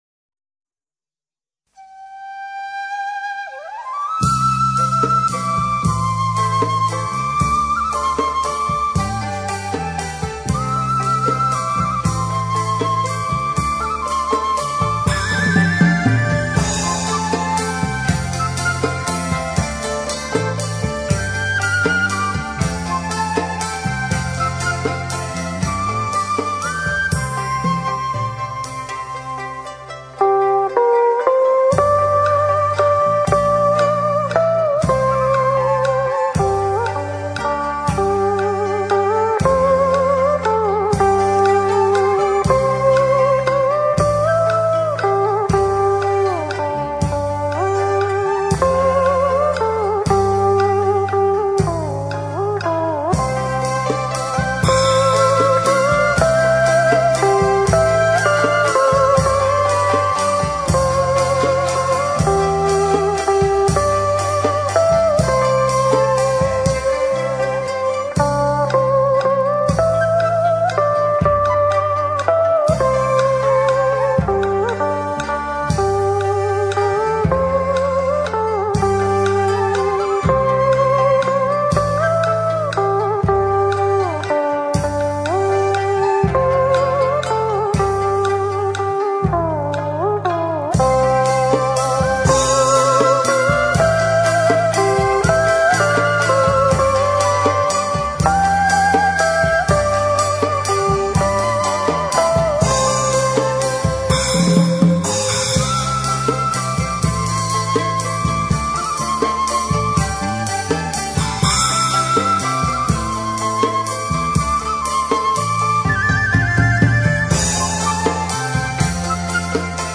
Nhạc không lời